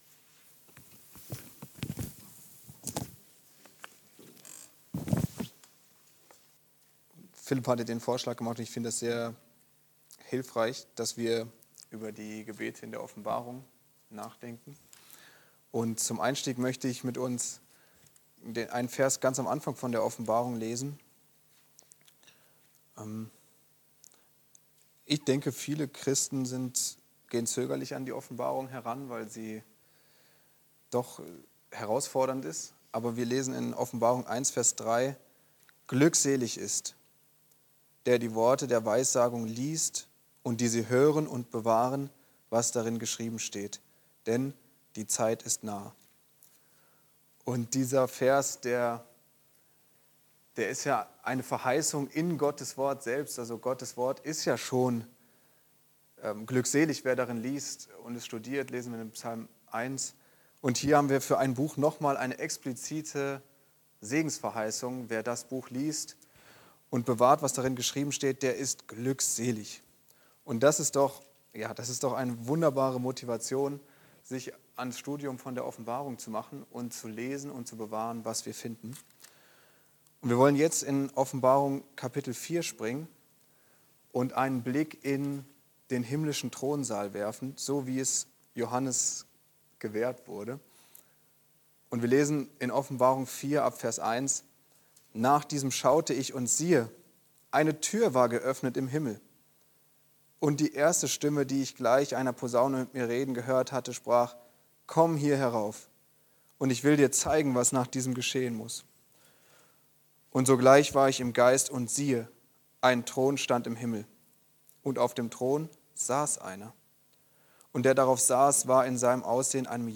Wirf deine Krone nieder! (Andacht Gebetstunde)